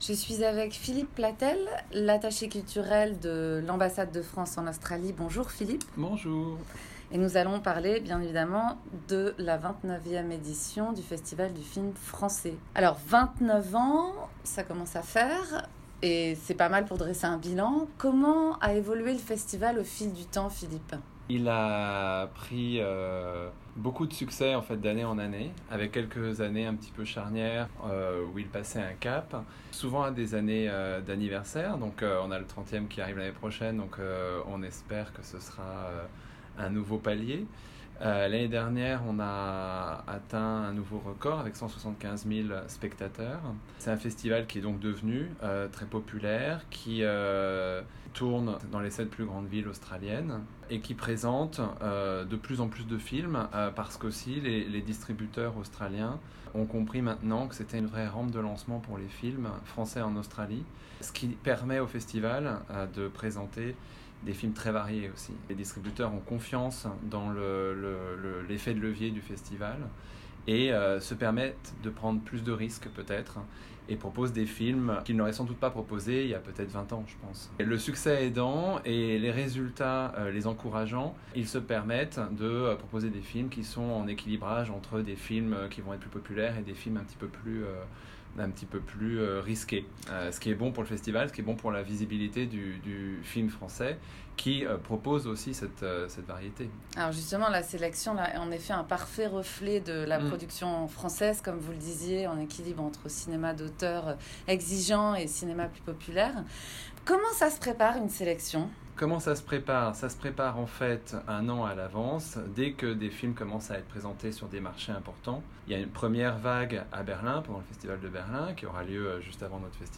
Première partie d'une longue interview puisqu'on vous l'a dit, vous saurez tout.